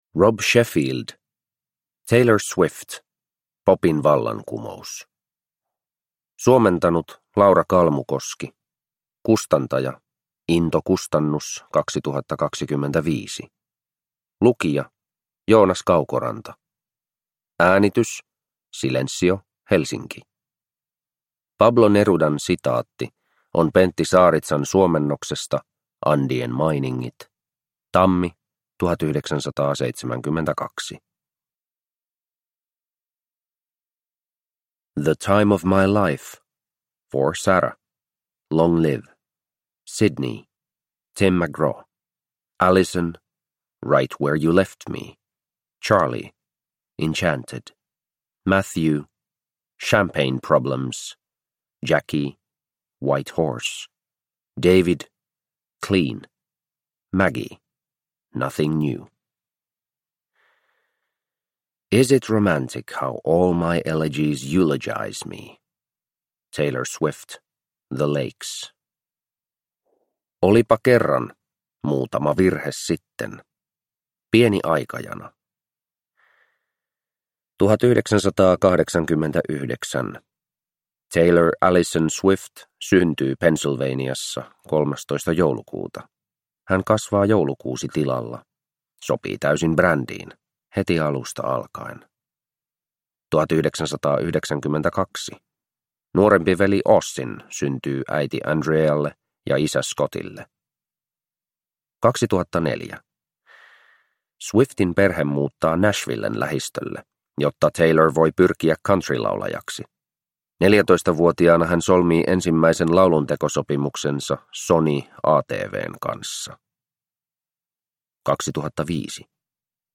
Taylor Swift – Ljudbok